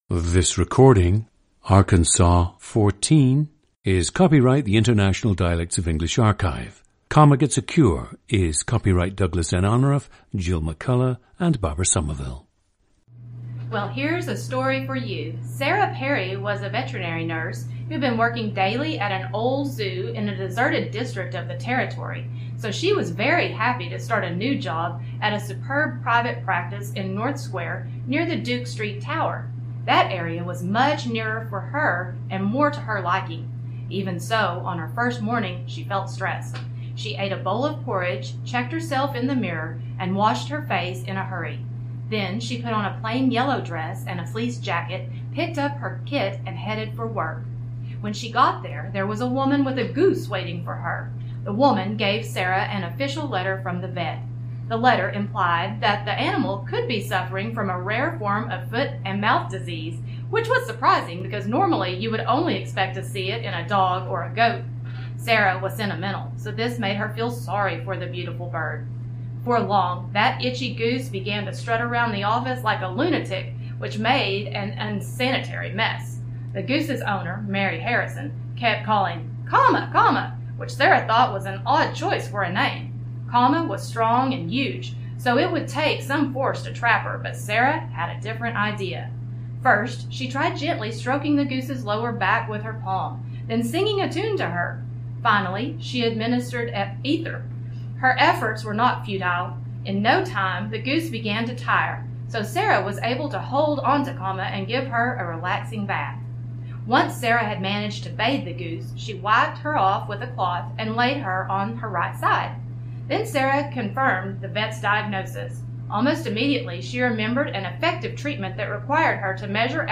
GENDER: female
OTHER INFLUENCES ON SPEECH:
Subject maintains a wide breadth of space in the back of mouth, with the tongue in a relatively low position. This tongue position causes some diphthongs to change their intrinsic qualities.
The /u/ (goose) vowel is stressed, lengthened, and occasionally has a schwa /ə/ (comma) inserted before it (duke, you, into).
/r/ is incredibly strong, sometimes overtaking the previous vowel (superb).
/p/ and /k/ initial plosives are hit with extra force.
The recordings average four minutes in length and feature both the reading of one of two standard passages, and some unscripted speech.